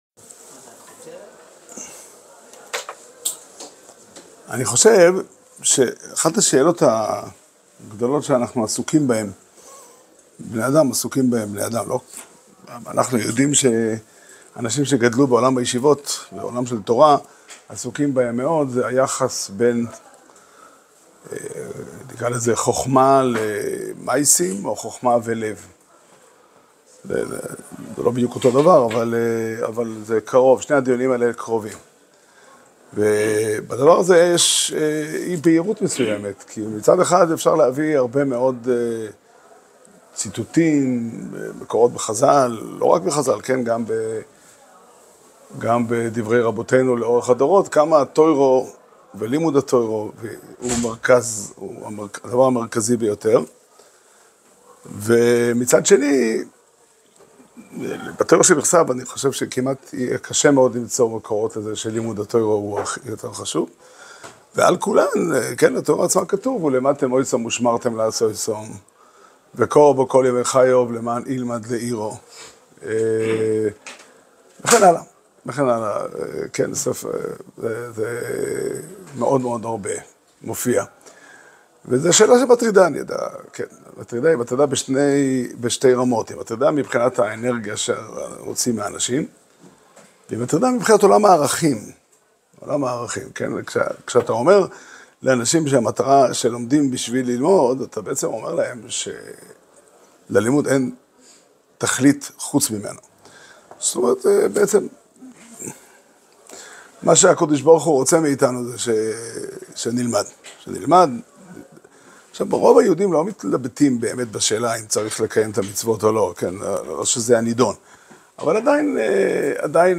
שיעור שנמסר בבית המדרש פתחי עולם בתאריך י"ד שבט תשפ"ה